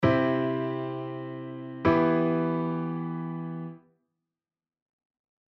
↓は、C⇒Emです。
いきなりC⇒Emと並べても、Emが主役になった感はまったくありませんね。